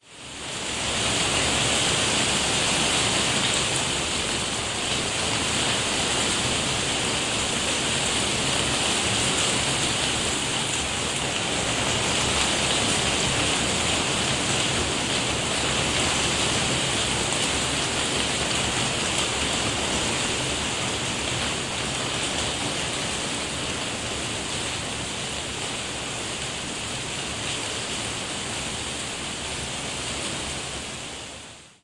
电池玩具猕猴桃 " Bass1
描述：从一个简单的电池玩具中录制的，是用一个猕猴桃代替的音调电阻！
标签： 音乐学院-incongrue 低音 电路弯曲
声道立体声